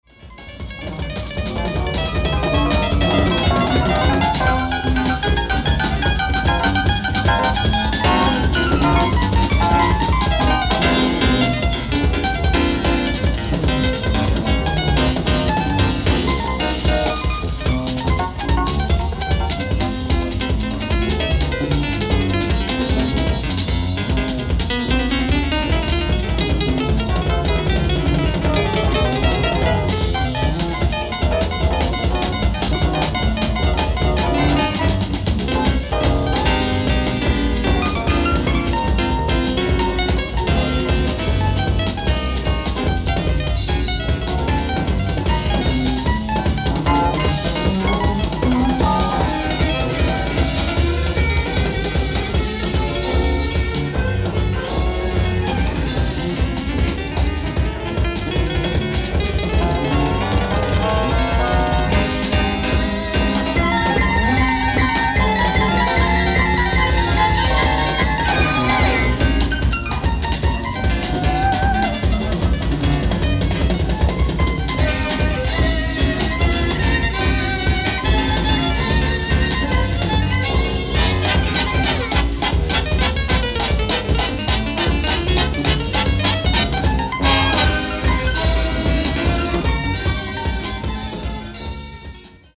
ライブ・アット・ジャズジャンボリー、ワルシャワ、ポーランド 06/27/1992
楽曲毎にレストア修正を施しています！！
※試聴用に実際より音質を落としています。